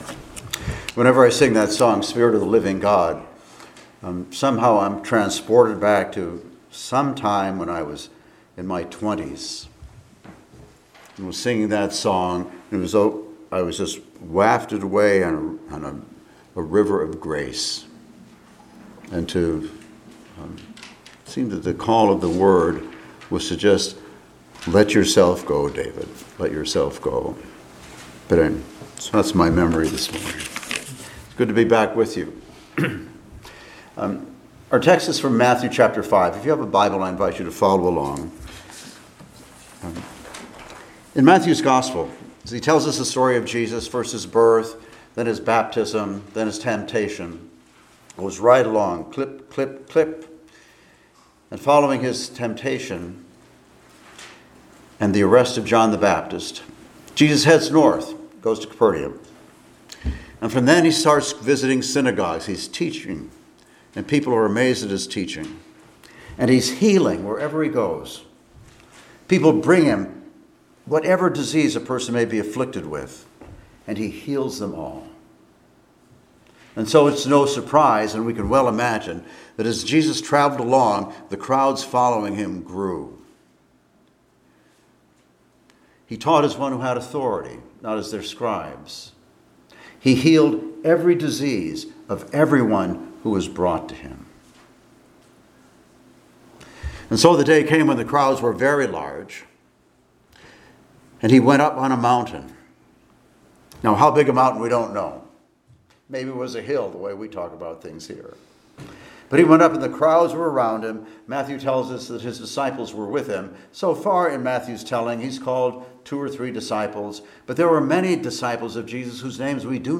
Passage: Mathew 5:13-20 Service Type: Sunday Morning Worship